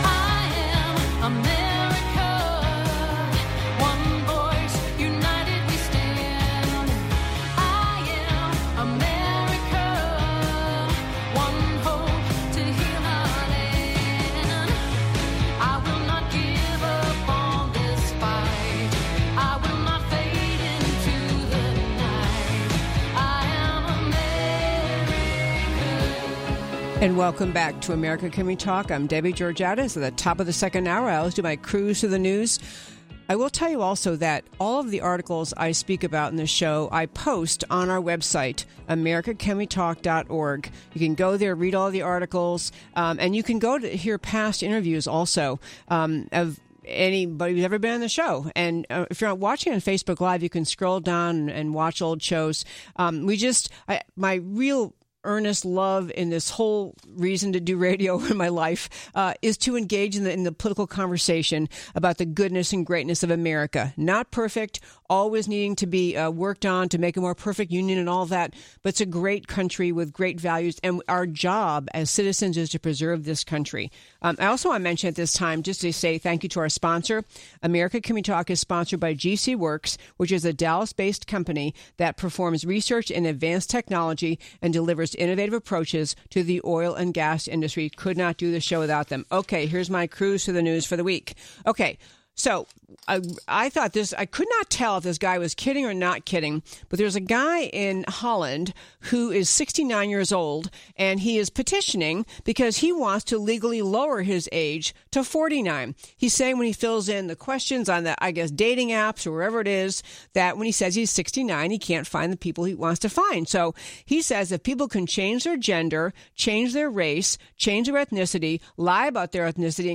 Guest interview – Dinesh D’Souza, Author, Speaker, Documentary Filmmaker